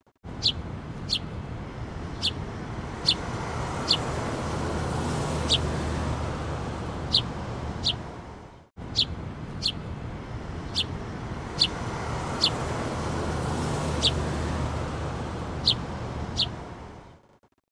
Tiếng chim Hót trong Công viên thành phố
Thể loại: Tiếng thiên nhiên
Description: Tiếng chim Hót trong Công viên thành phố là âm thanh tiếng chim hót tự nhiên, có tiếng xe cộ, tiếng ồn của không gian xung quanh. Đây là âm thanh môi trường, âm thanh tự nhiên của thành phố- nơi có tiếng chim hót lanh lảnh xen lẫn tiếng hoạt động của con người.
tieng-chim-hot-trong-cong-vien-thanh-pho-www_tiengdong_com.mp3